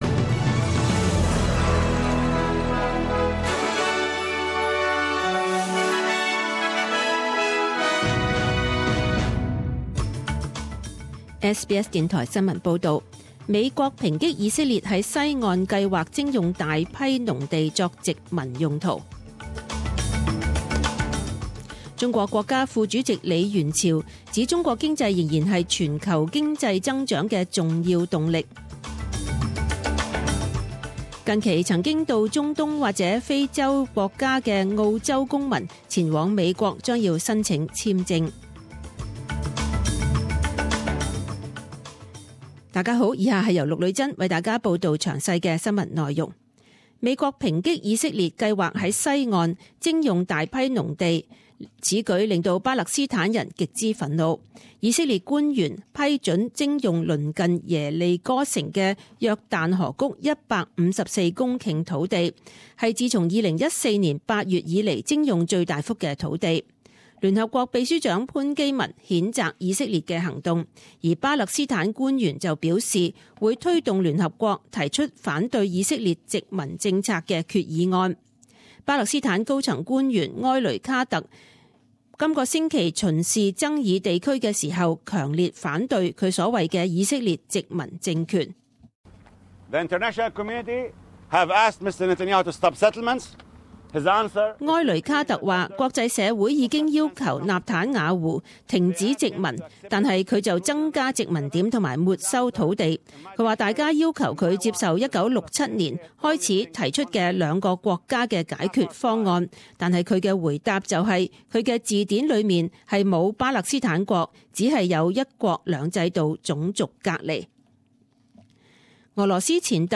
十点钟新闻报导 （一月二十二日）